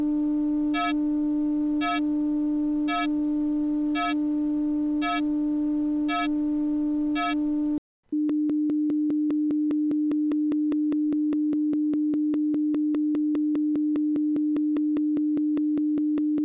音频播放和麦克风录制声音清晰、正常、但在音频播放期间除外 返回有一个~300BPM 快速滴答声,并且在麦克风录音期间有一个重复的声音蜂鸣,大约每0.8秒。
我已附加了一个 MP3文件、其中显示了声音奇怪。
前8秒是芯片通过耳机麦克风录制300Hz 正弦波(我将扬声器对着正在播放音调的麦克风提起)。 您可以每0.8秒左右听到一次剧烈的蜂鸣声。
接下来的8秒将芯片的音频输出录制到我的音频接口中。 播放相同的300Hz 正弦波、您可以听到~320BPM 嘀嗒声。
PCM2912A-problem.mp3